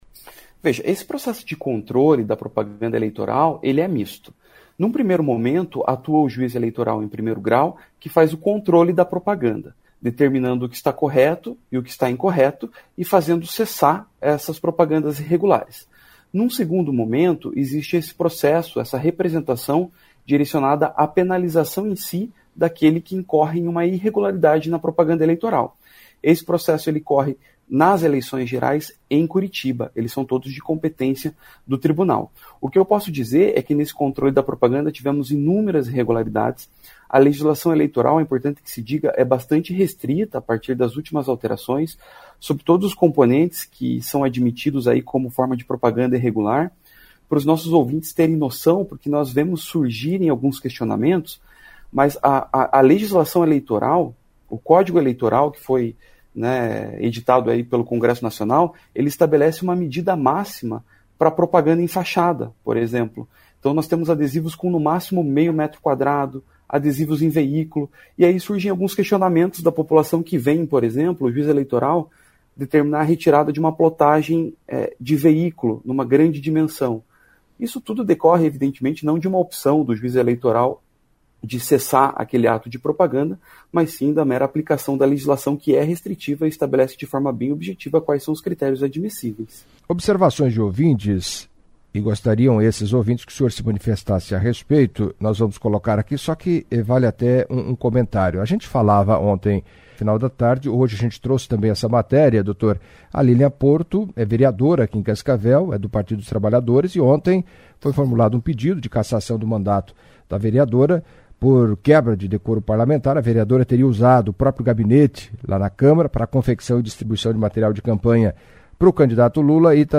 Em entrevista à CBN Cascavel nesta sexta-feira (28) o juiz eleitoral, Phellipe Muller, comentou sobre a denúncia feita contra a vereadora petista, Liliam Porto, que na tarde de quinta-feira (27) foi alvo de um pedido de cassação do mandato por suposto crime eleitoral.